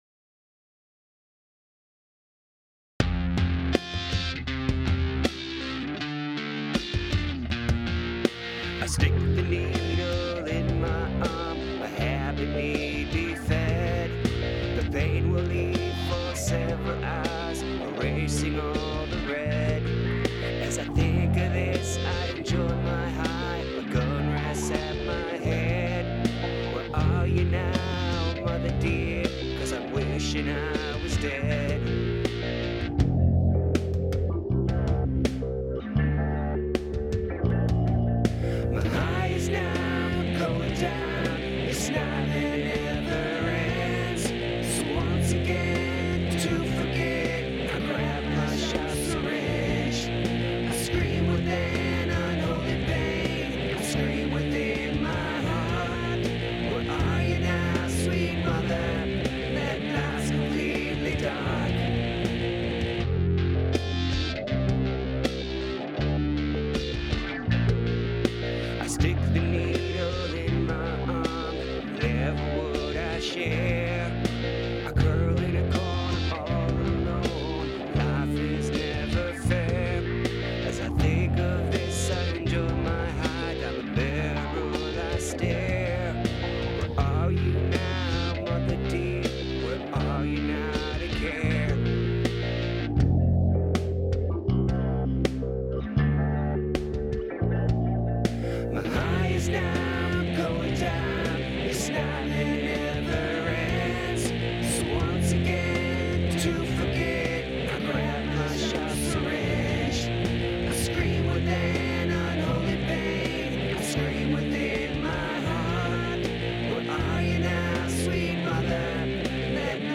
The first 10 seconds sounds more like a synth bass just doing a low sine wave than a bass guitar. 10 seconds in, it sounds like a bass.
Vocals. bass. keys. guits. drums sans the cymbals cause they were crazy shhhh.